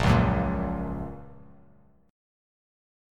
Bbm#5 chord